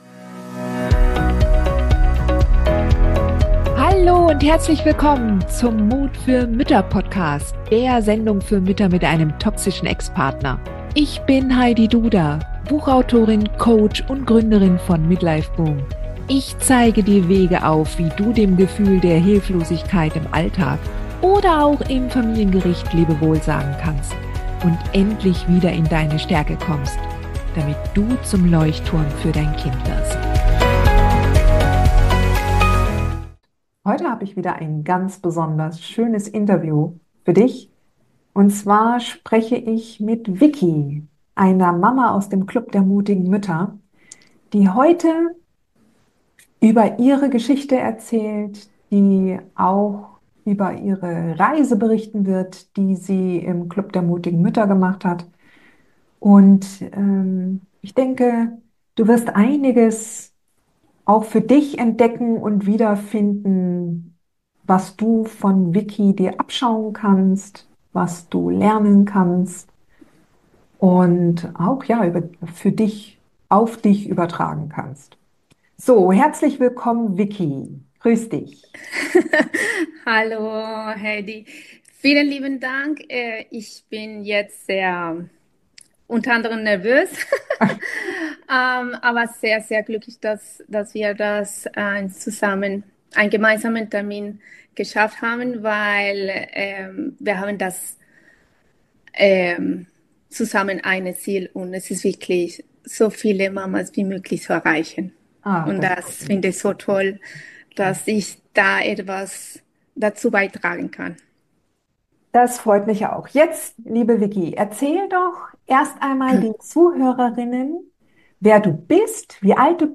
Ein Mutmacher-Interview für alle Frauen, die sich fragen, ob sie stark genug sind.